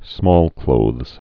(smôlklōthz, -klōz)